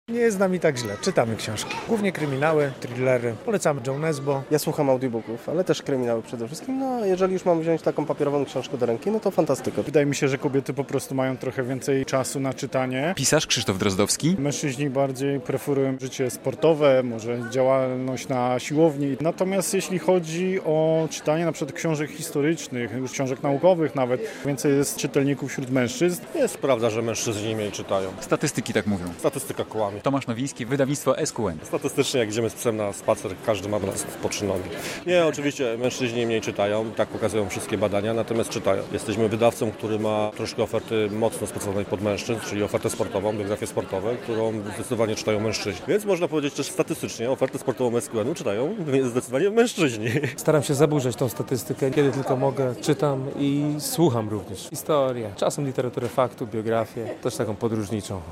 Czy mężczyźni czytają mniej książek? - relacja